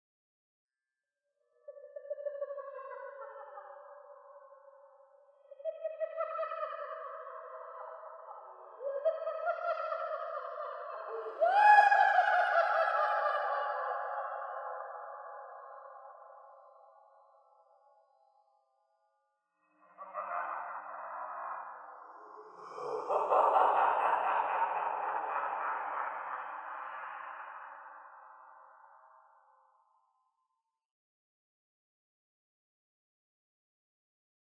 Nightmare Laughter | Sneak On The Lot
Eerie Processed Laughter. Various Male.